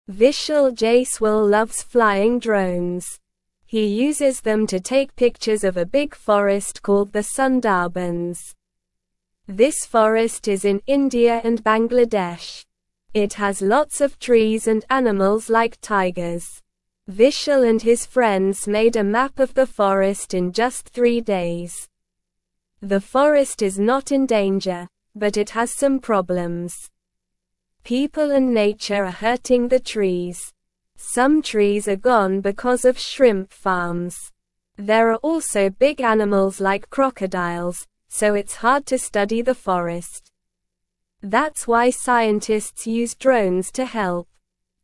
Slow